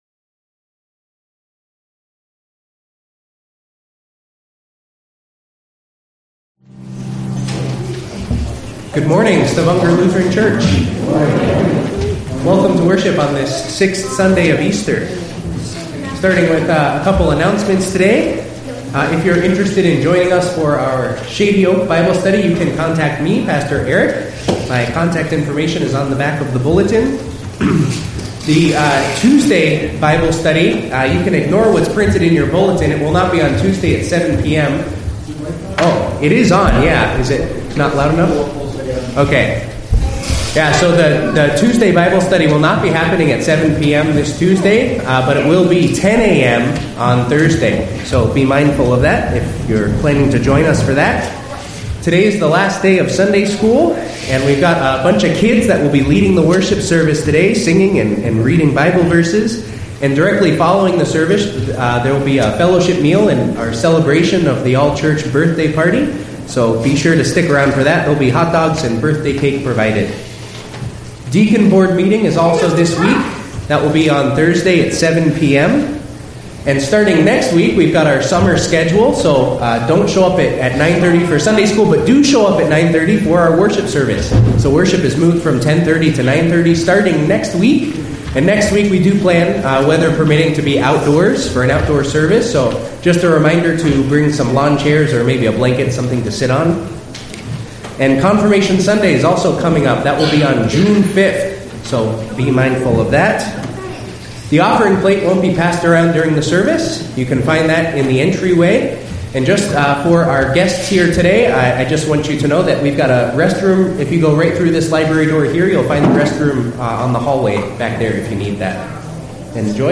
Message: “Sunday School Program” from SS Kids Singing
From Series: "Sunday Worship"